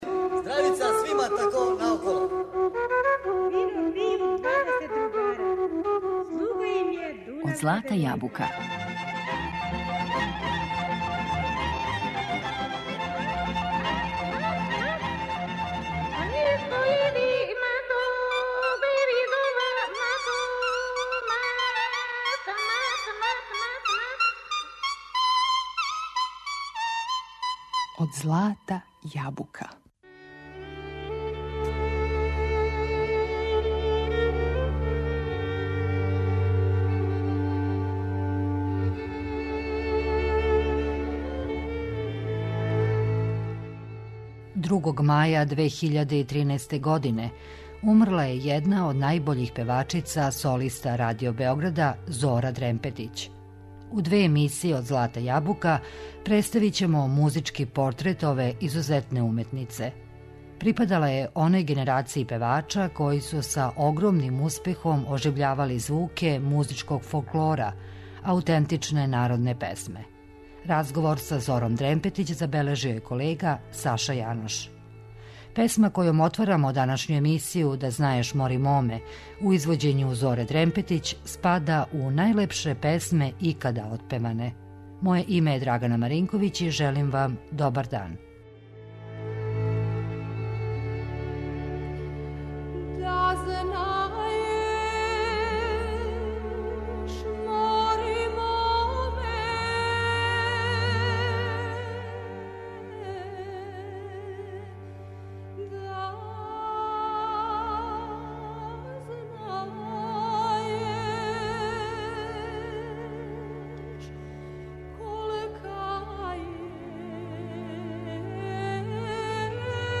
Припада оној генерацији певача који су са огромним успехом оживљавали звуке музичког фолклора аутентичне народне песме.